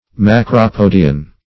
Macropodian \Mac`ro*po"di*an\, n.